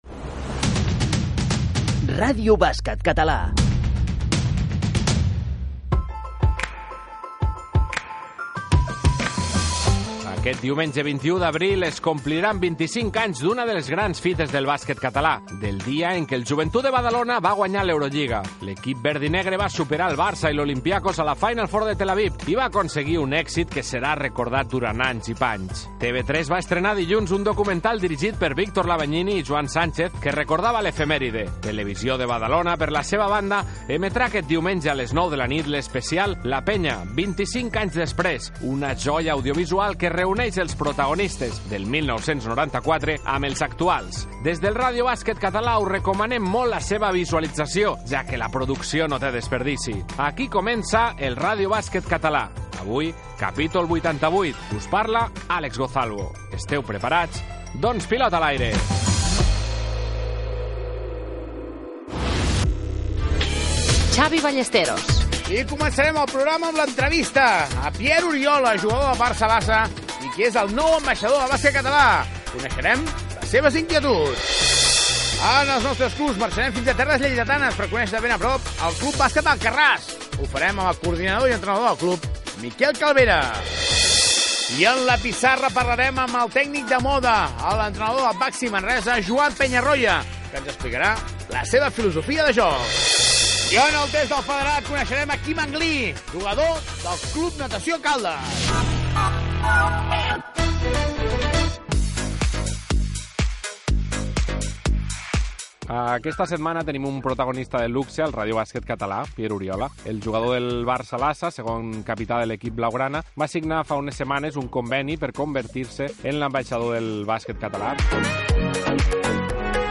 Indicatiu del programa, presentació inicial comentant els programes televisius especials el Joventut de Badalona, sumari de continguts, entrevista al jugador del FC Barcelona Pierre Oriola, que s'ha convertit en ambaixador del Bàsquet Català
Esportiu